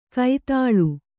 ♪ kai tāḷu